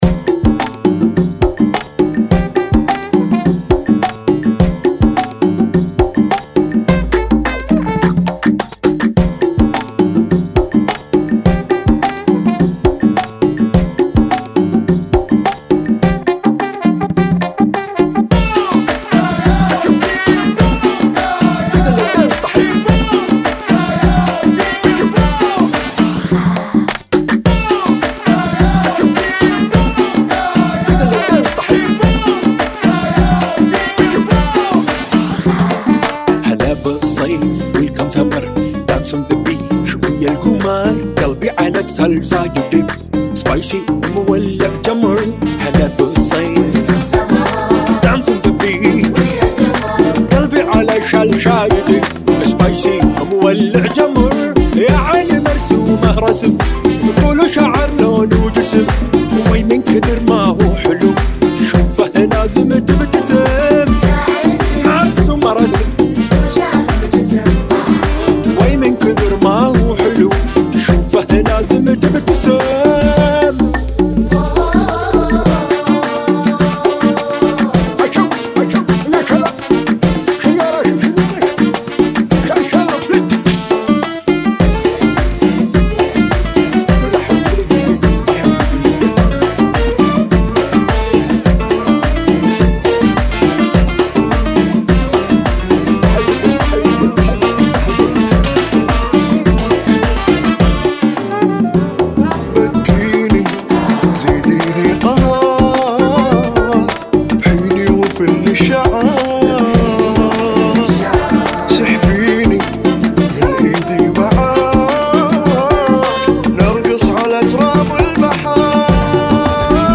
[ 105 Bpm ]